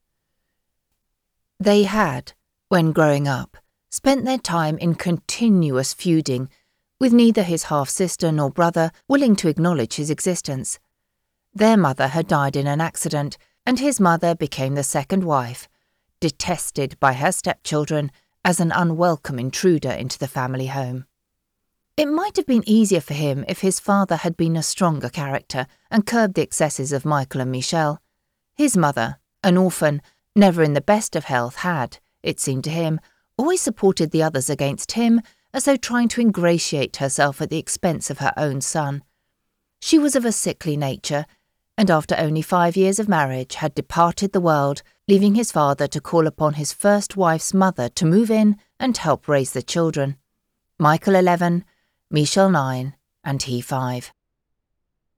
Current, versatile, engaging, rich, warm. Natural Yorkshire tones if required, as is heightened RP - BBC 1940's newsreader style. Regional and European accents if needed....
1119Literary_Fiction_Short_Story.mp3